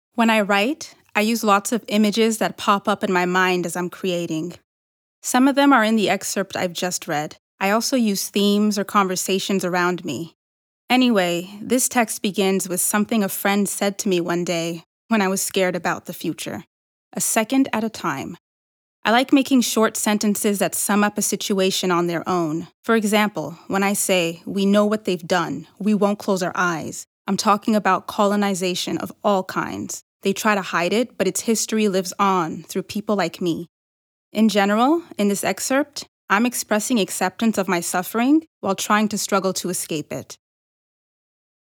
Narration - EN